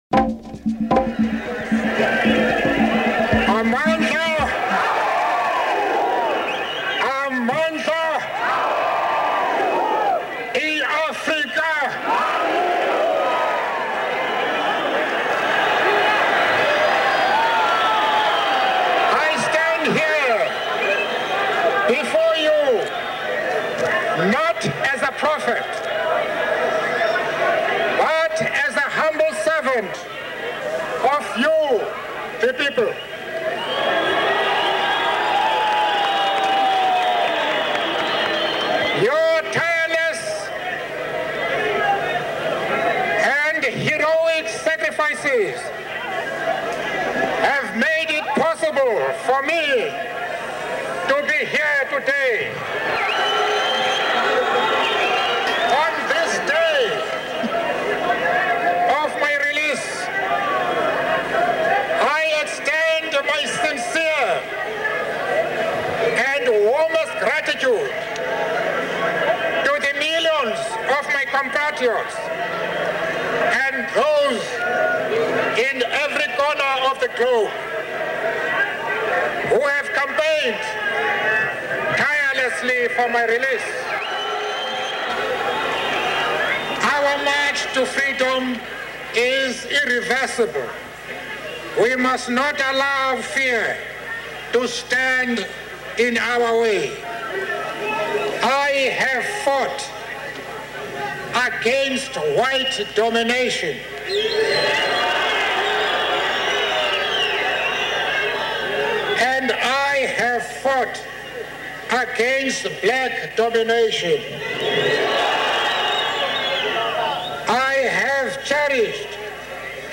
Nelson Mandela upon release from Prison
Nelson_Mandela_release.mp3